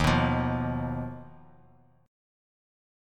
Ebsus2#5 chord